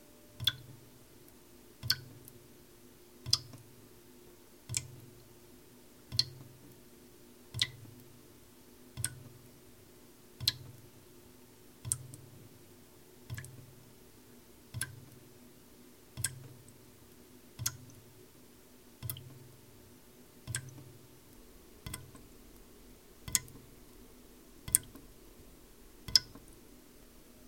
描述：杯中滴水
Tag: 场记录 滴剂 滴流 d RIP